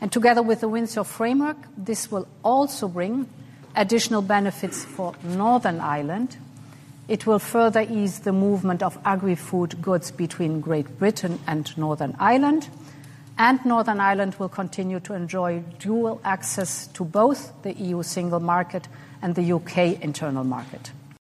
EU Commission President Ursula von der Leyen says changes to animal and food checks will benefit Northern Ireland: